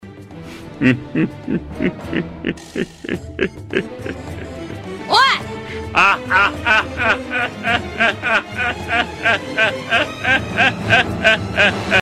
Play, download and share Evil Crocodile 2 original sound button!!!!
evil-crocodile-2.mp3